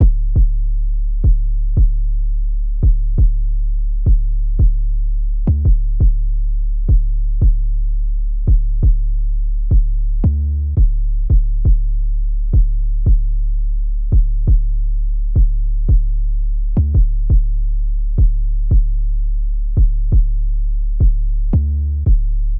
808 loop.wav